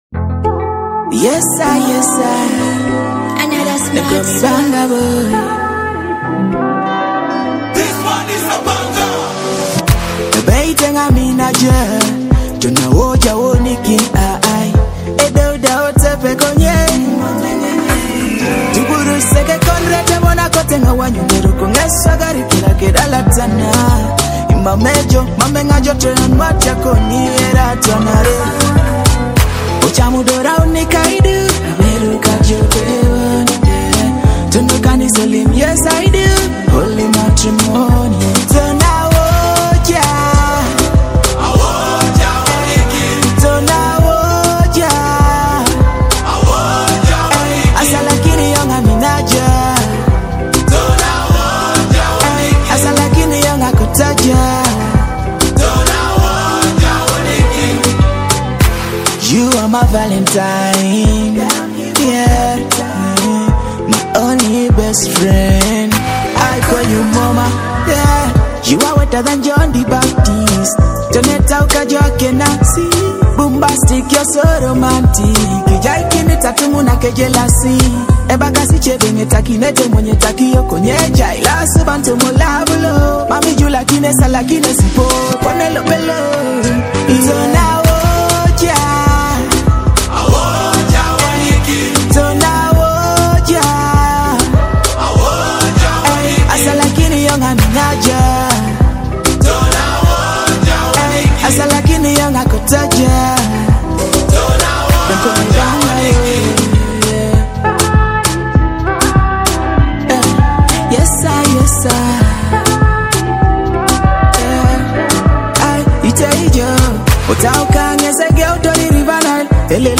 Afrobeat–Dancehall love hit